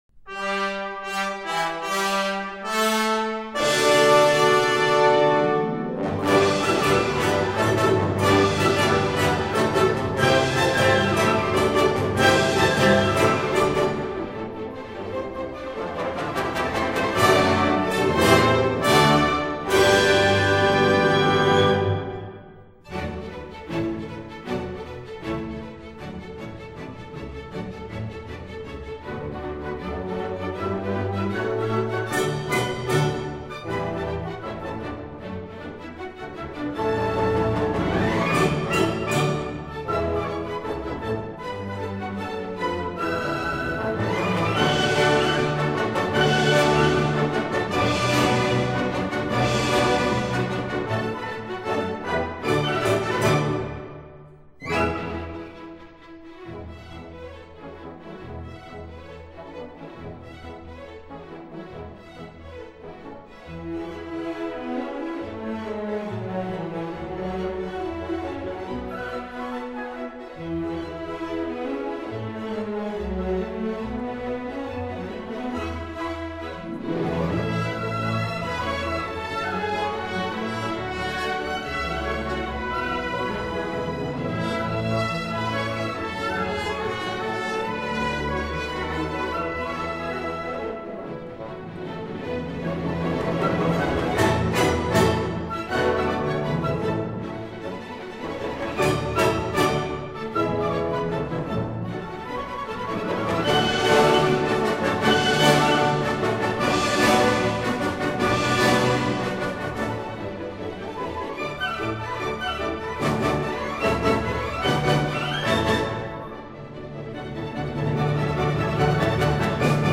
旋律内涵深刻，听来却通俗易懂.